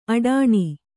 ♪ aḍāṇi